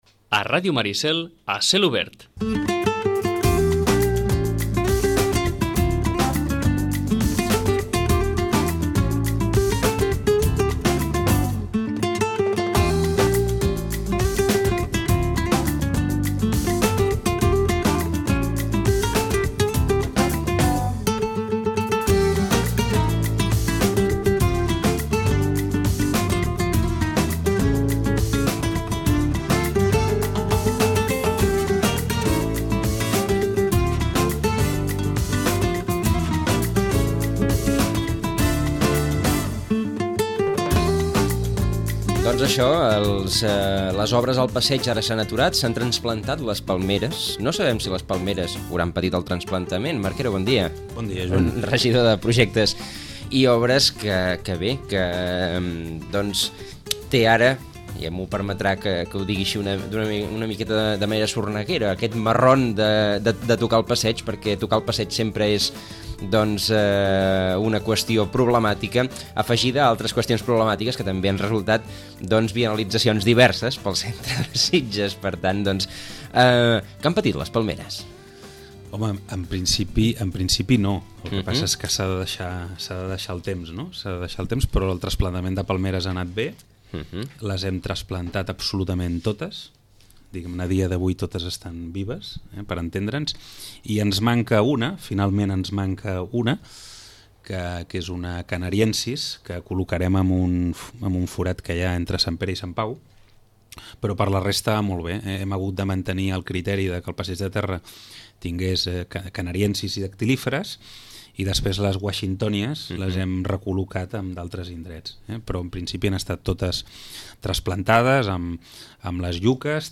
El regidor de projectes i obres, Marc Quero, explica la finalització de la primera fase de la remodelació del passeig de la Ribera. L’actuació s’ha de reanudar passat l’estiu.